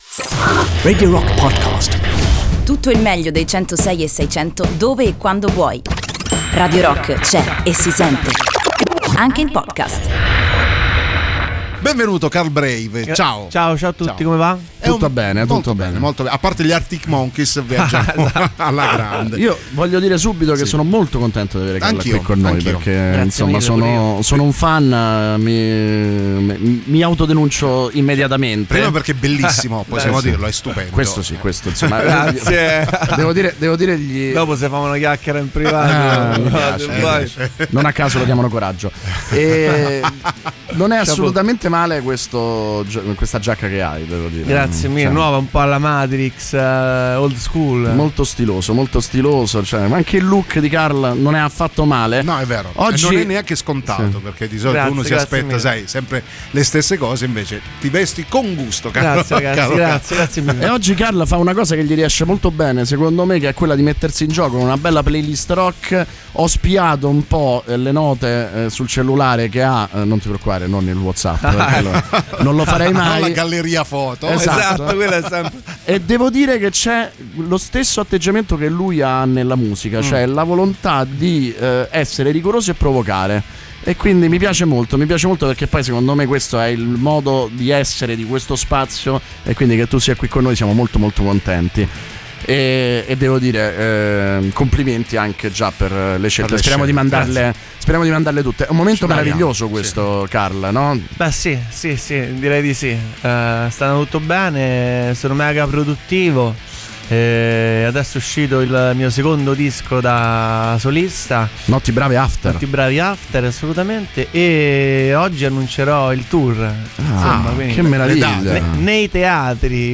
Intervista: Carl Brave (12-12-18)